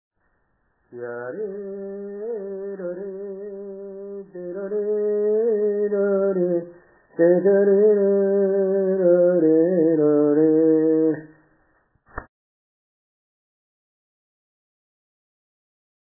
08)  Toque de procesión tarareado.